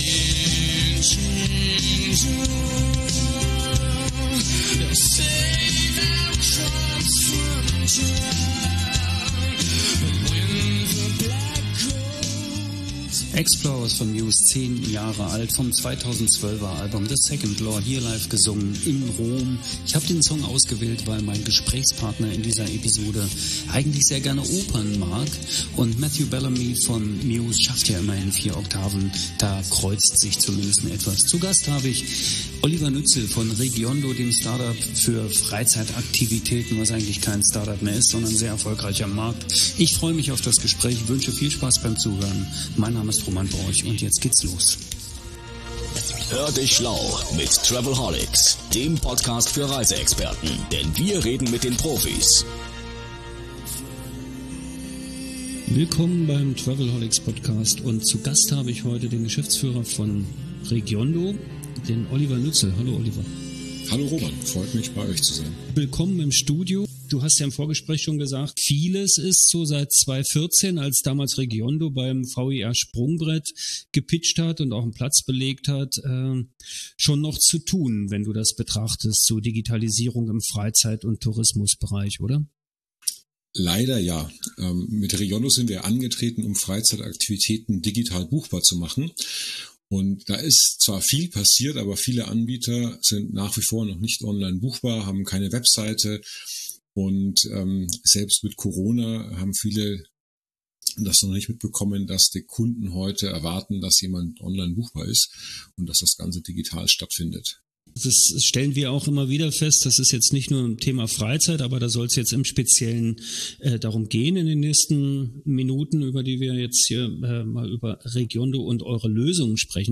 Sachkundig, fundiert und sehr unterhaltsam werden die aktuellen Herausforderungen und die von REGIONDO angebotenen Lösungen besprochen.